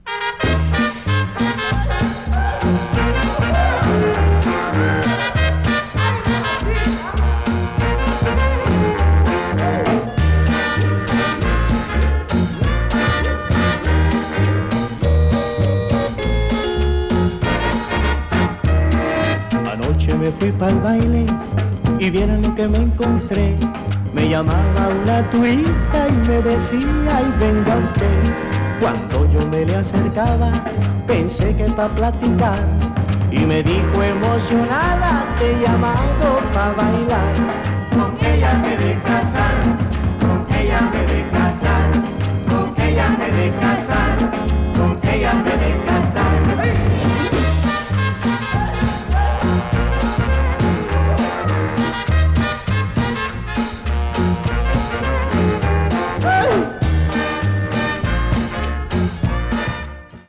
チープな高音のオルガンがとても特徴的！